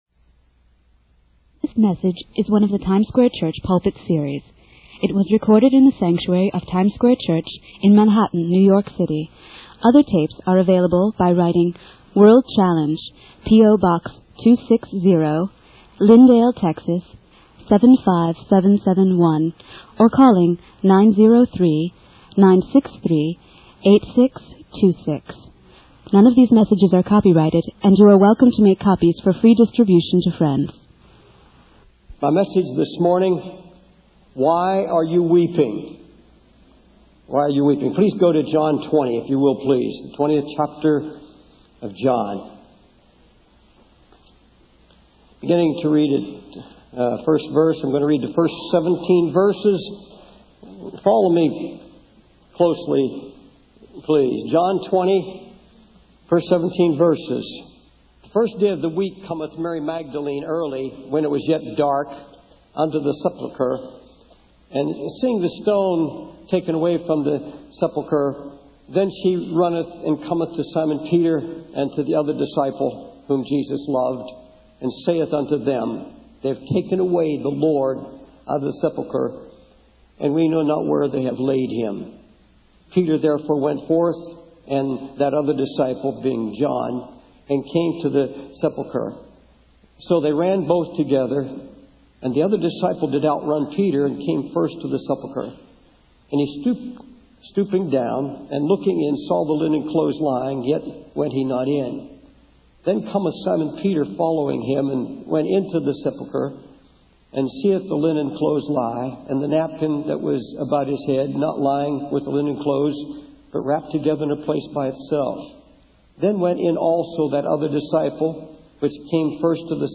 In this sermon, the preacher encourages the congregation to raise their hands and thank Jesus for his love. He emphasizes the need for repentance and opening one's heart to receive God's protection and love. The preacher prays for strength and victory in the battles ahead and urges the congregation to bless the Lord. The sermon concludes with a message about the importance of living in victory and having a smiling, victorious faith.